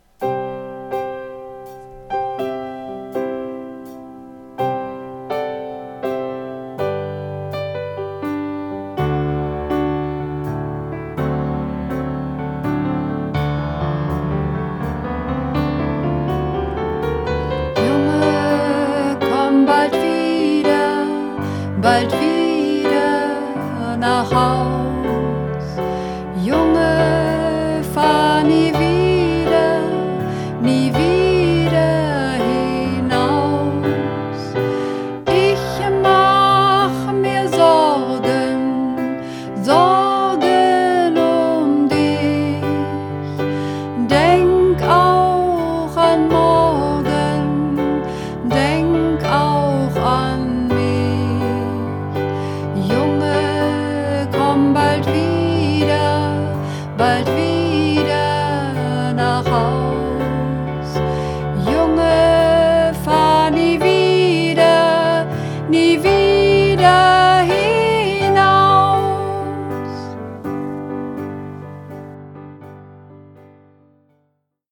Übungsaufnahmen - Junge, komm bald wieder